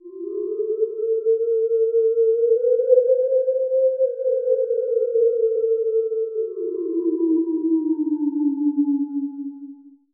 ho_ghost_theremin_02_hpx
Ghostly howling sounds resonate with reverb.